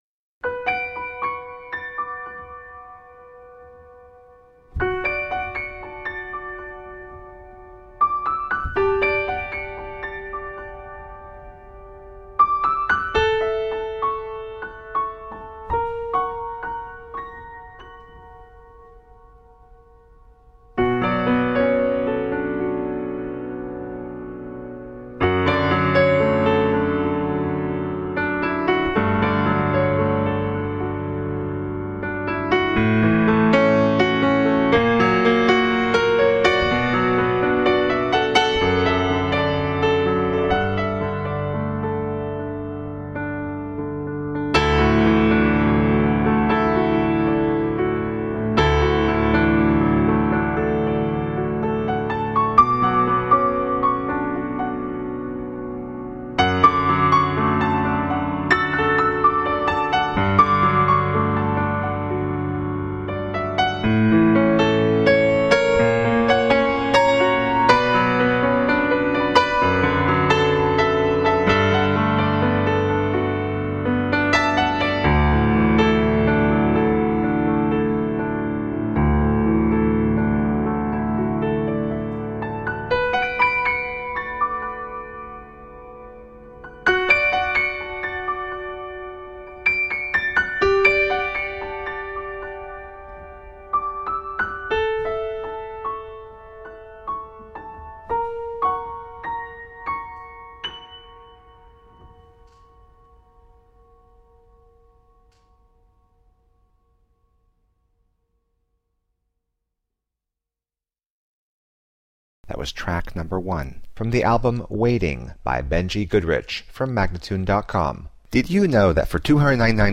Solo piano to relax with.